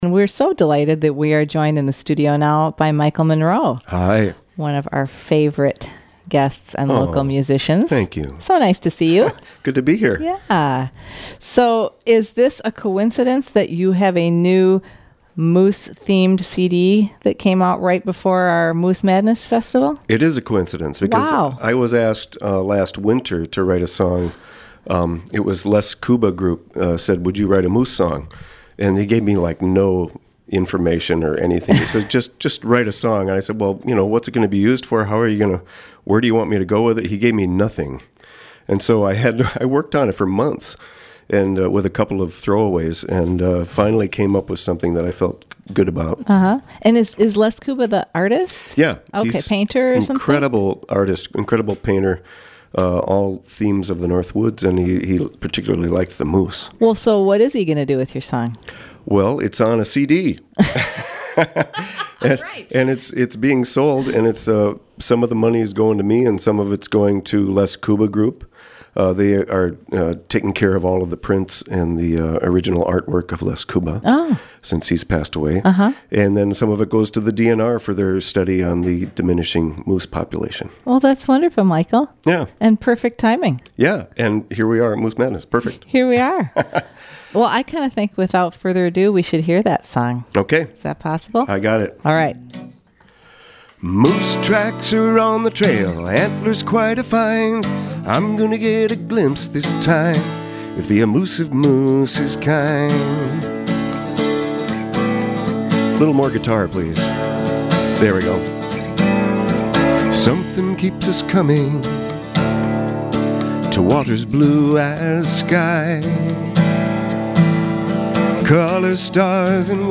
Live Music Archive